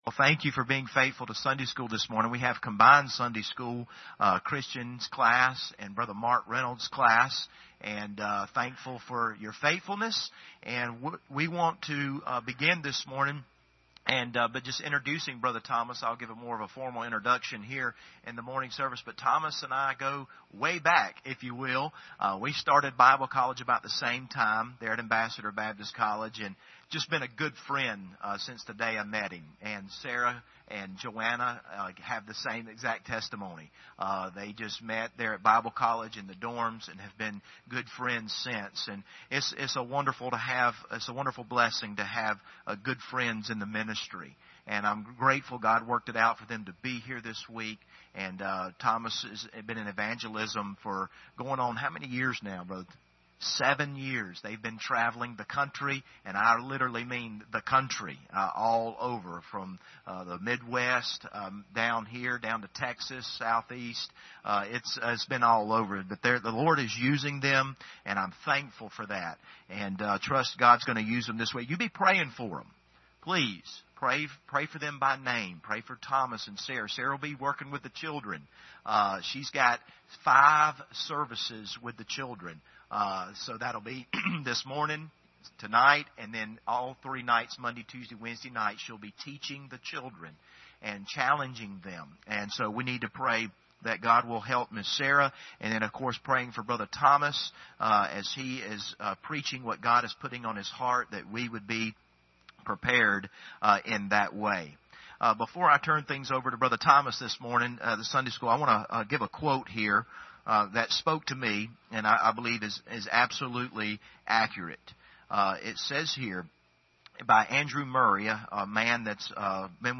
Passage: 2 Chronicles 34 Service Type: Sunday School Hour Download Files Bulletin Topics